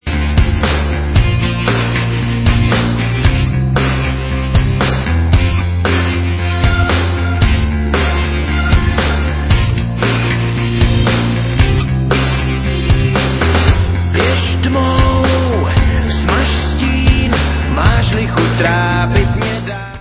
vocals, guitars
drums, vocals
keyboards
bass